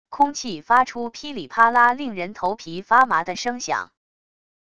空气发出噼里啪啦令人头皮发麻的声响wav音频